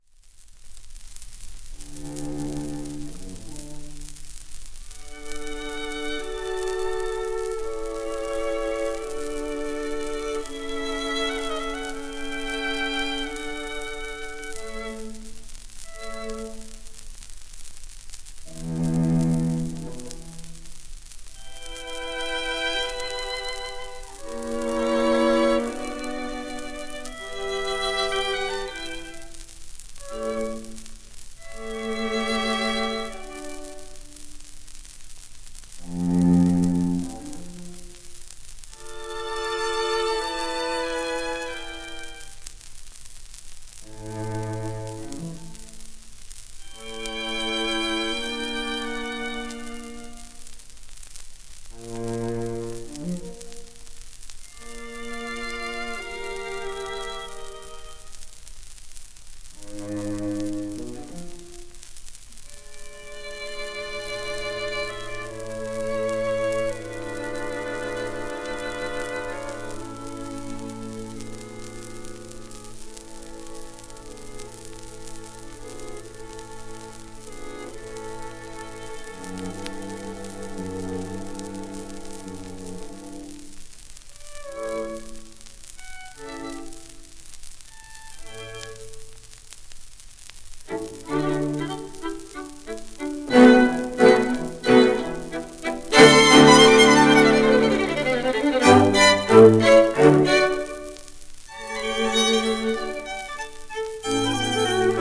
Mischa Schneider(Cello)
Boris Kroyt(Viola), Milton Katims(Viola)
Violin